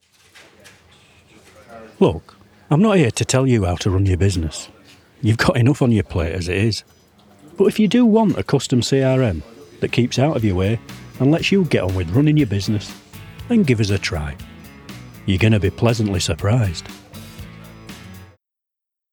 Voice Over Projects